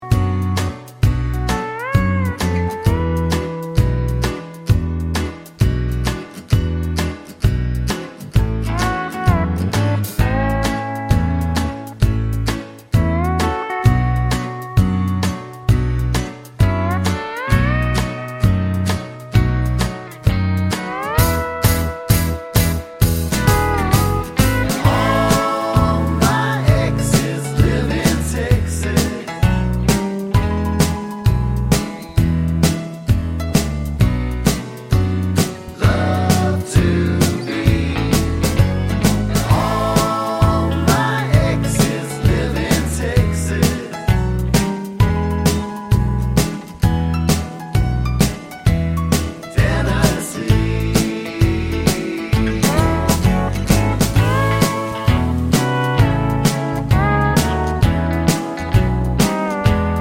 no Backing Vocals Country (Male) 3:16 Buy £1.50